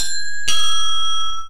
announcement ding dong football pa request soccer stadium sound effect free sound royalty free Sound Effects